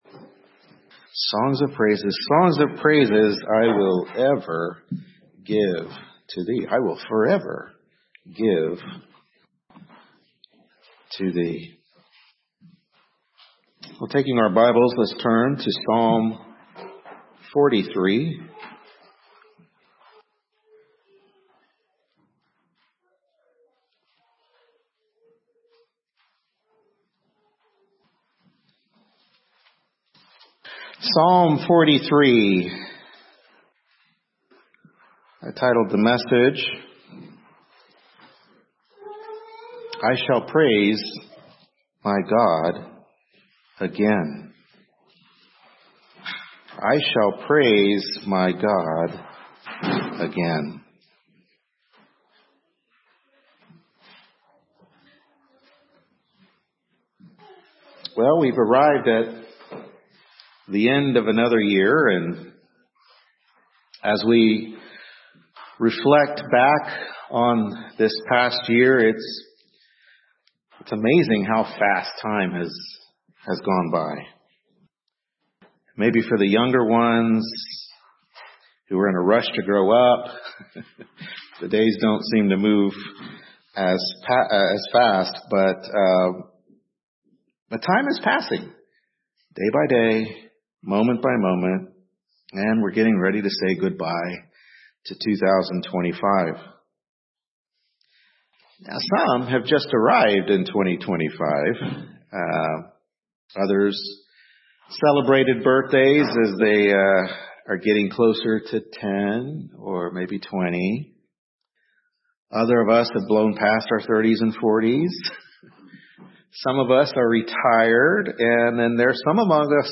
Service Type: Morning Worship Service